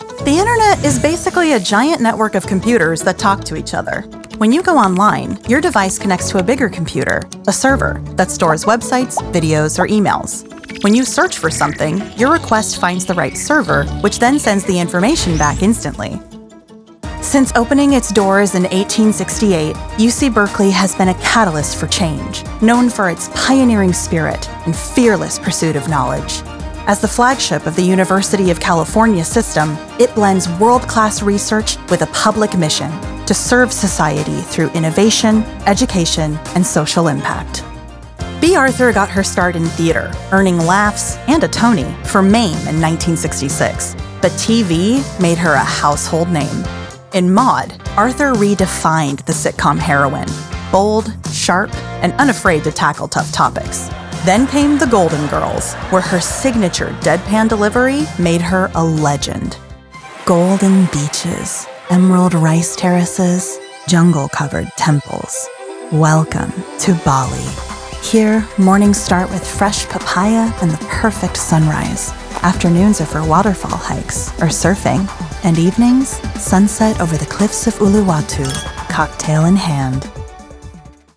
Narration Demo
In narration, her voice shifts seamlessly into something steady, inviting, and grounded.